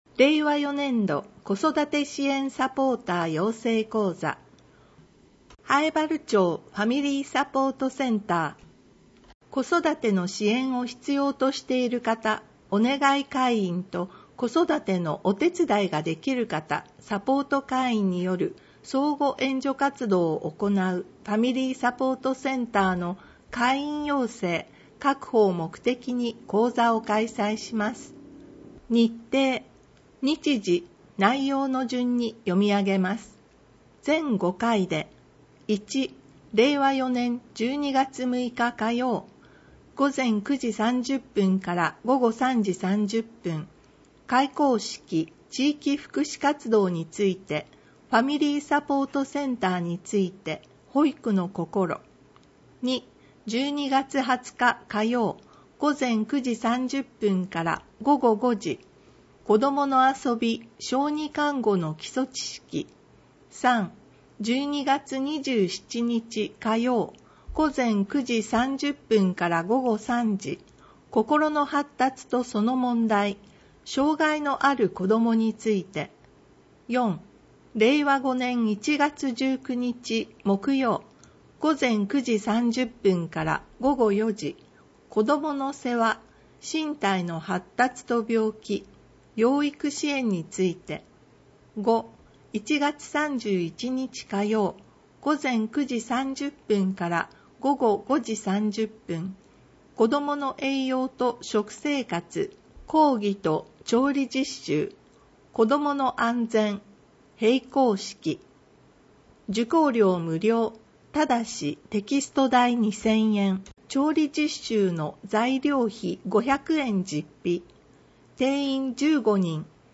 音訳ファイル